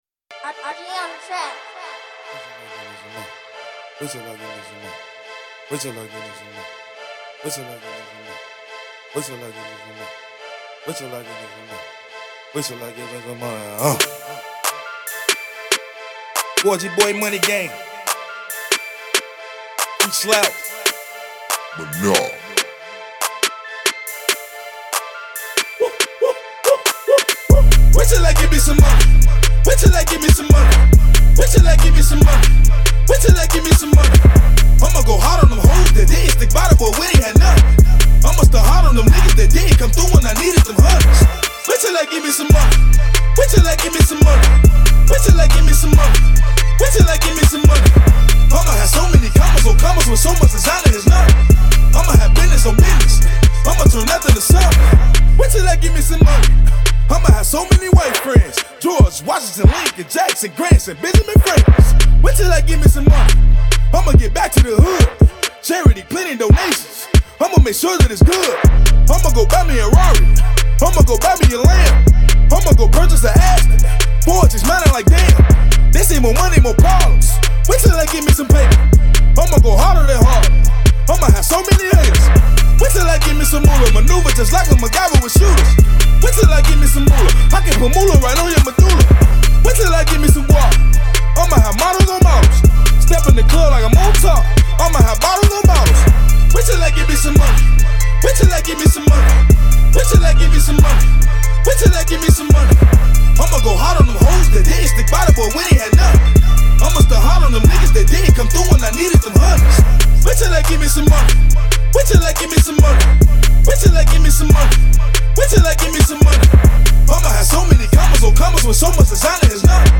Club Banger
Hiphop